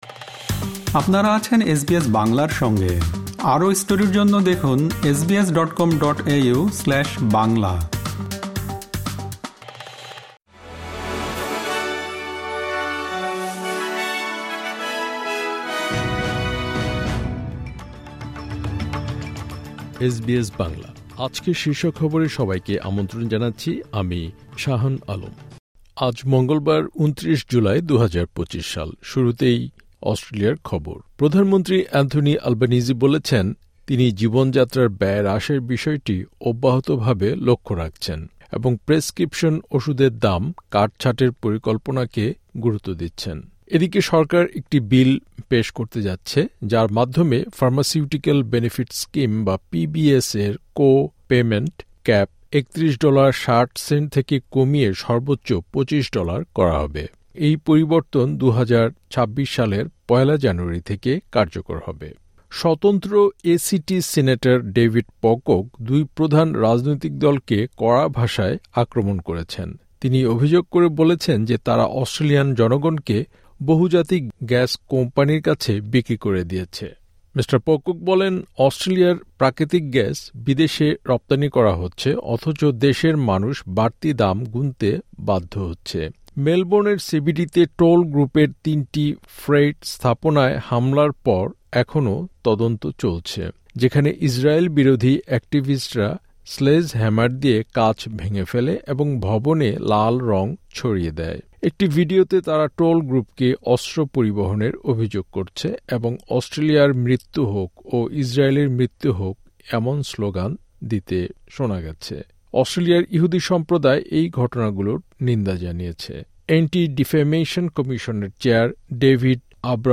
এসবিএস বাংলা শীর্ষ খবর: ২৯ জুলাই, ২০২৫